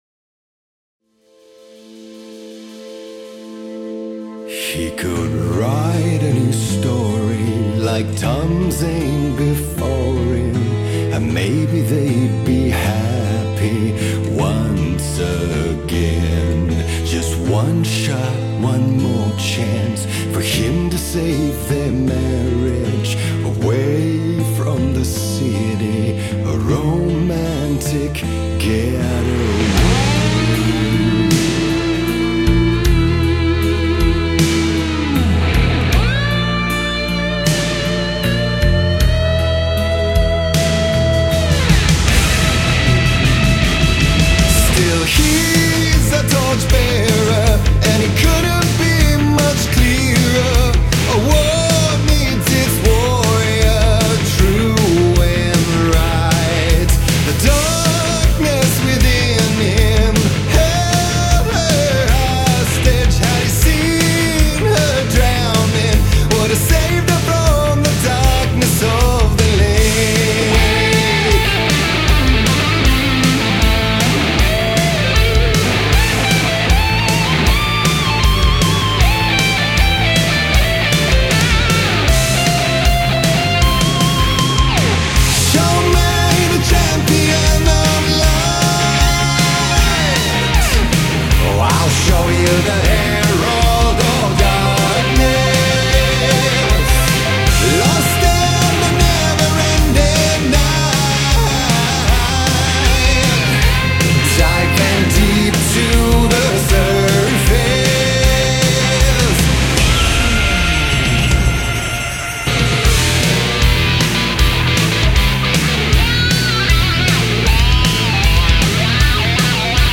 BPM156-156
Audio QualityPerfect (High Quality)
Full Length Song (not arcade length cut)